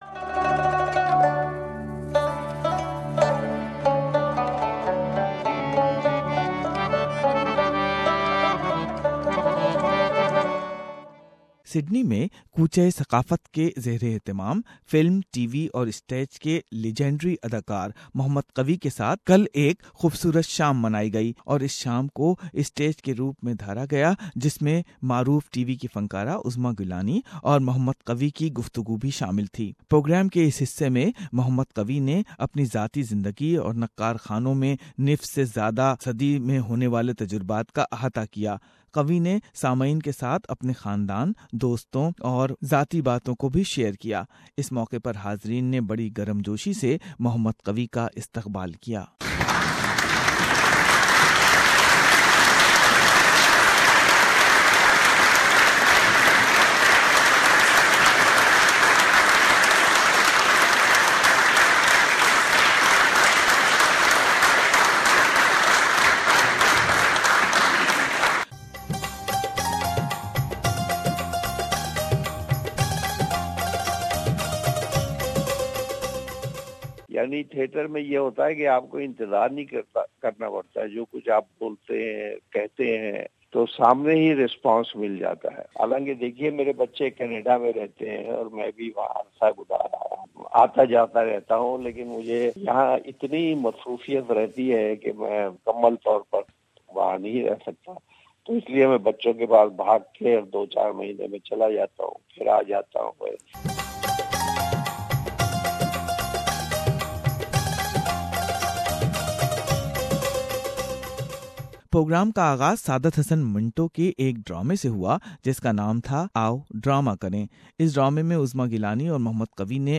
Legendary TV/Film and stage actor Qavi Khan and renowned TV actress Uzma Gilani Performed in Sydney stage program. Listen the audio highlights of of the show.